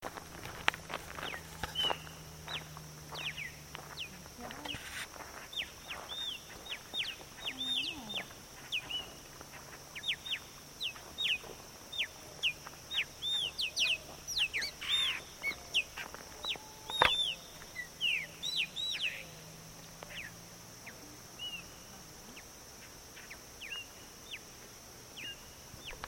Graúna (Gnorimopsar chopi)
Varios ejemplares sobrevolando el palmar al atardecer
Nome em Inglês: Chopi Blackbird
Localidade ou área protegida: Parque Nacional El Palmar
Condição: Selvagem
Certeza: Observado, Gravado Vocal